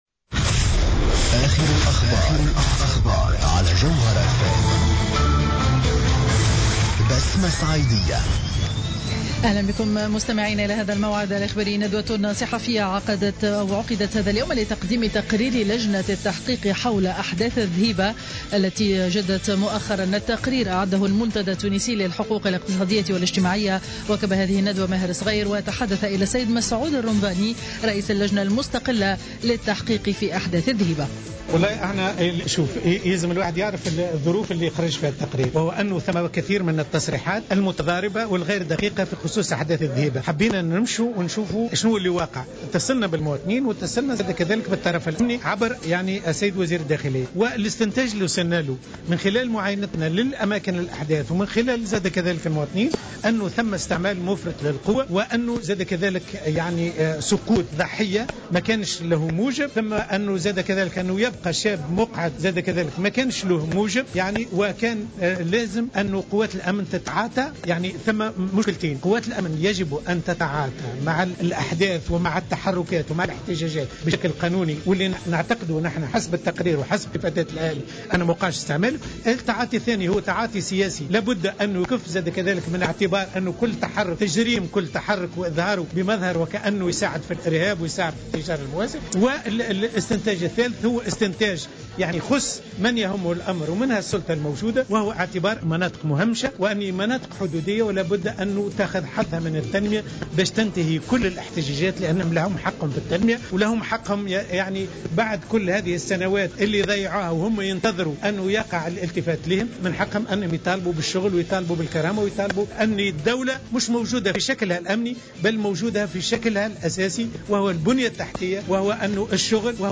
نشرة أخبار منتصف النهار ليوم الخميس 19 فيفري 2015